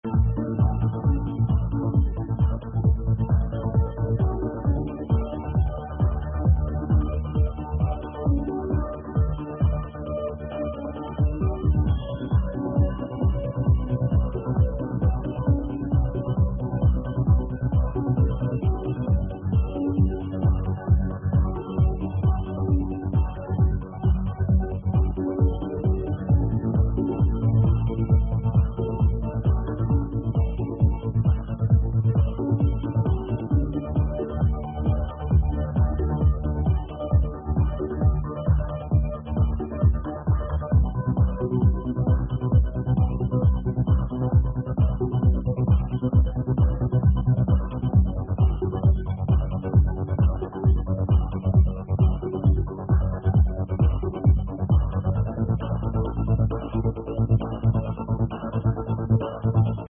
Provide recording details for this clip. Very low sound on this one.